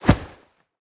throw-projectile-4.ogg